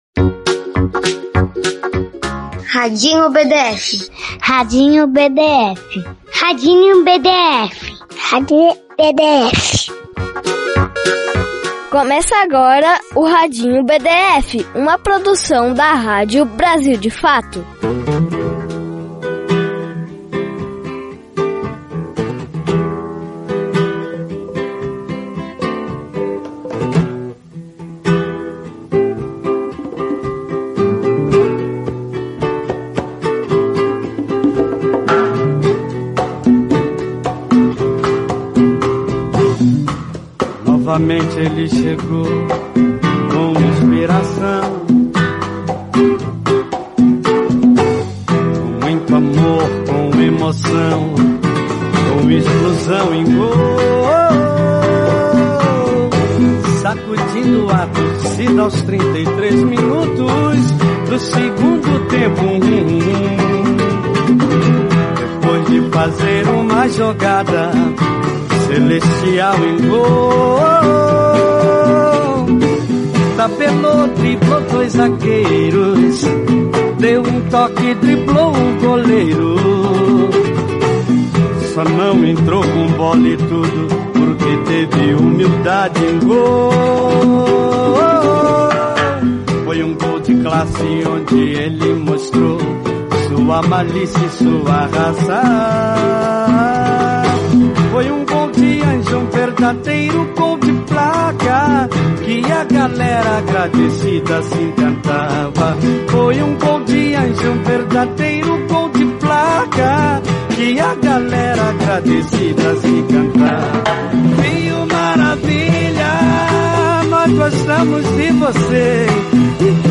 Radinho BdF conversa com atletas e crianças sobre as Olimpíadas de Tóquio